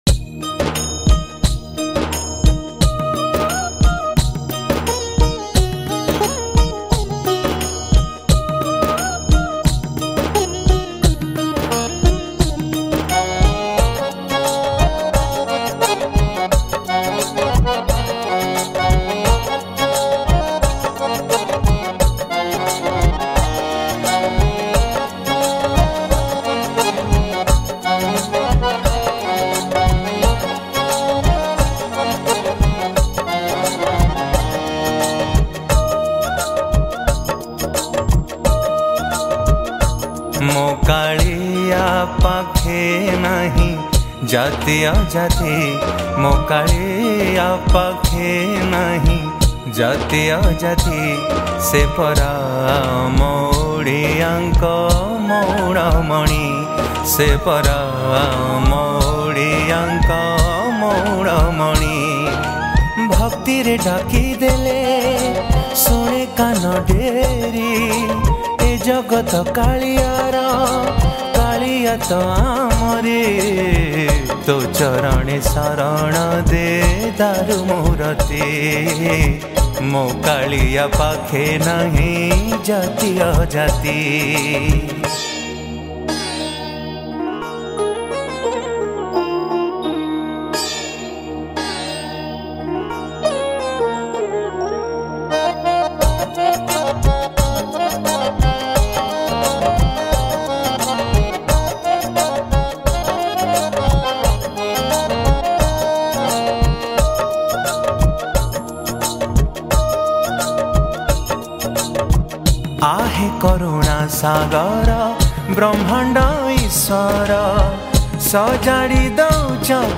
Ratha Yatra Odia Bhajan